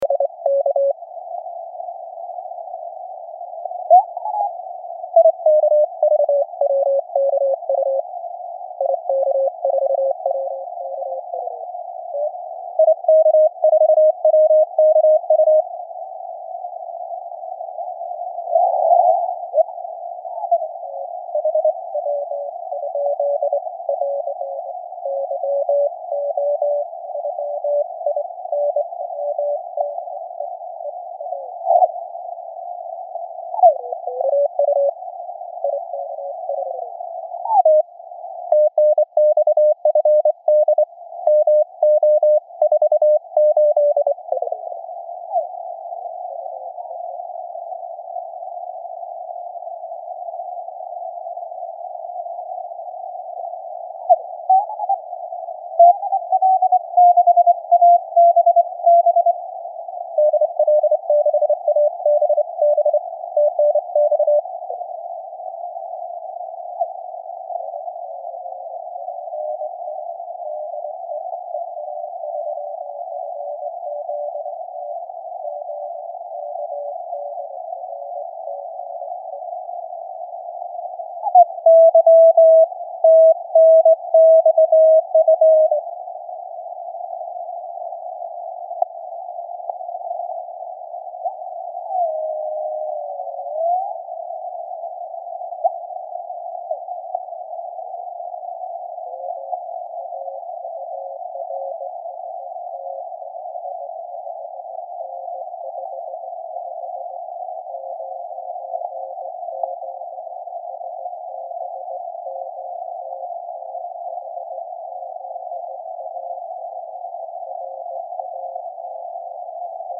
Запись с линейного выхода.
Перешел потом на SSB и CW на 14 мГц (на 40-ке прохода нет).
При SSB полоса 2.4 кГц, при CW 100, а в конце записи 50 Гц. Вроде как ничего звучит.